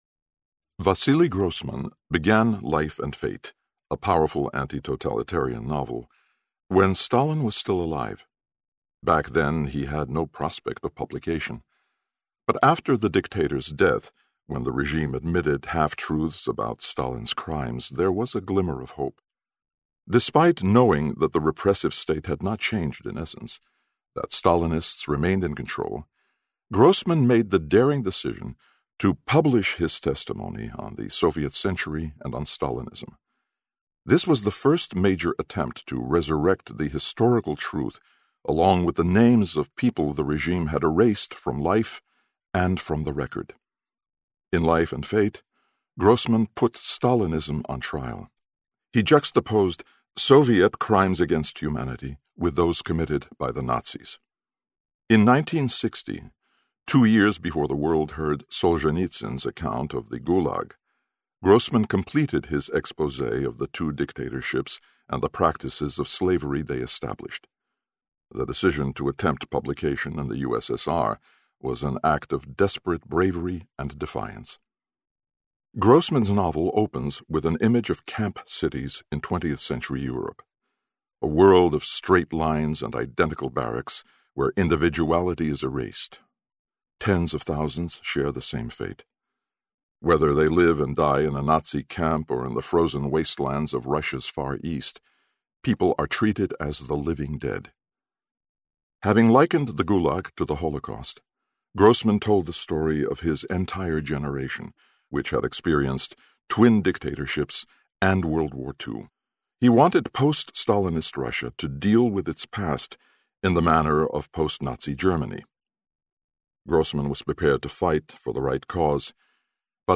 Audio Book Sample – Vasily Grossman and the Soviet Century